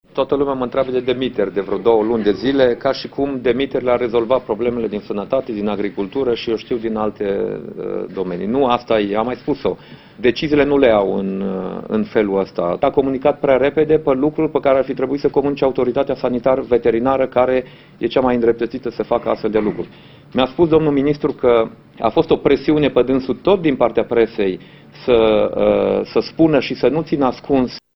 Întrebat, azi, la Cluj-Napoca, într-o conferinţă de presă dacă nu intenţionează să îl demită, Dacian Cioloş a răspuns că deciziile pe care le ia le pregăteşte înainte.